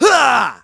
Riheet-Vox_Attack3.wav